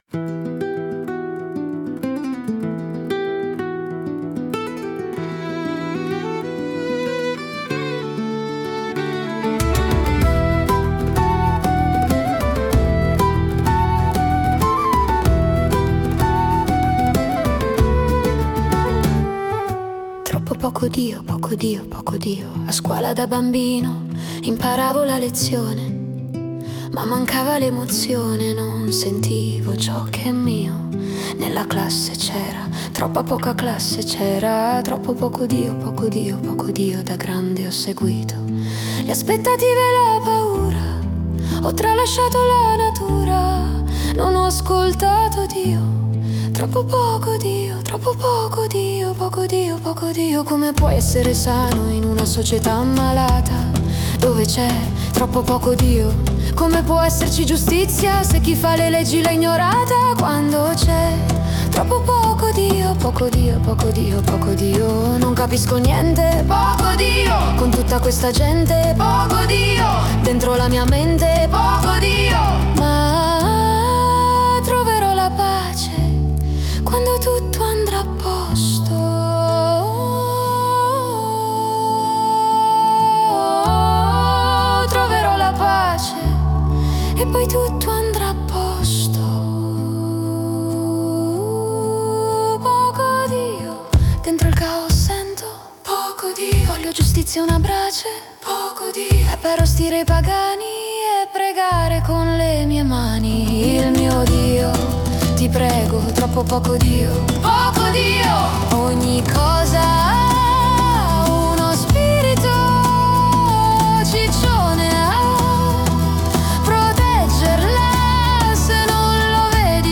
Ho scritto il testo e l'ai ha fatto il resto.
Non si capisce se la voce deve essere quella di un bambino o di una ragazza.
ragazza, ma ti è piaciuta o no?